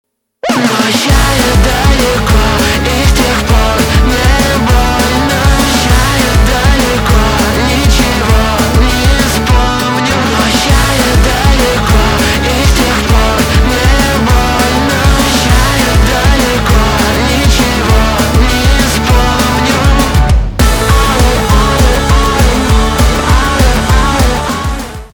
альтернатива
гитара , барабаны